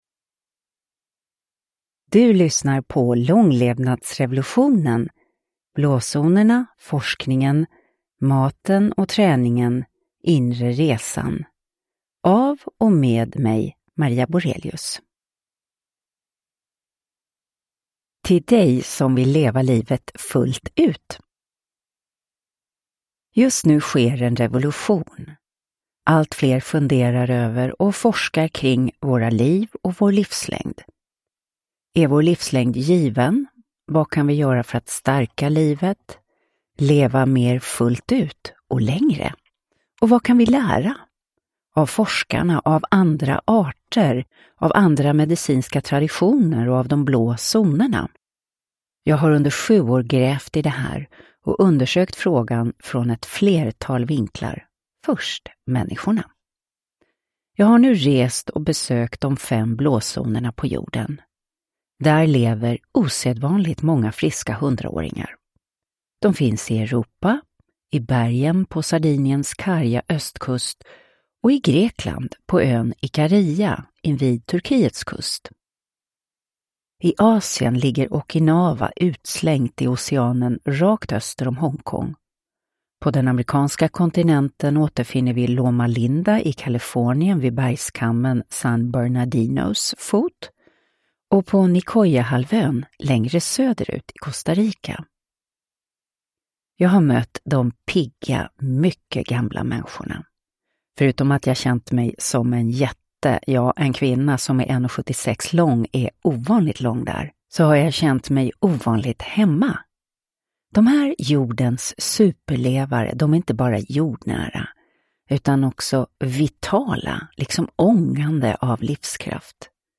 Långlevnadsrevolutionen – Ljudbok
Uppläsare: Maria Borelius